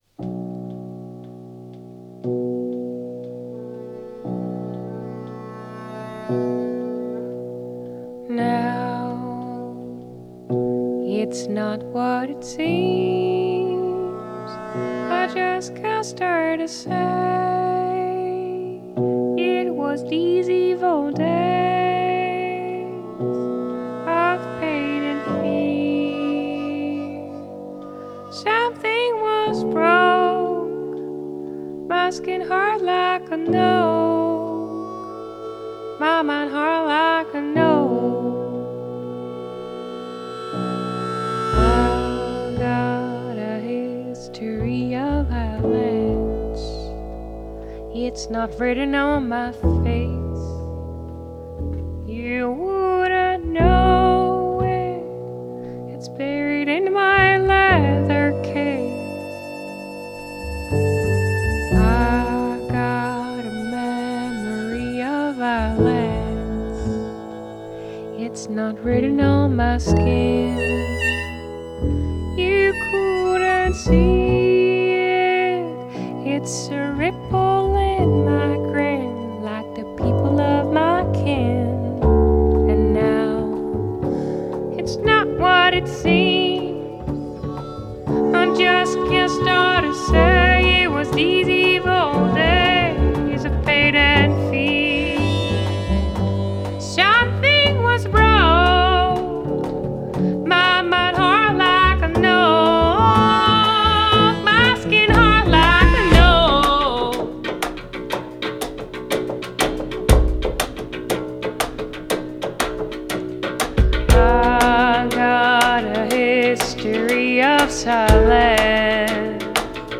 Genre: Folk, Indie, Country Folk, Female Vocal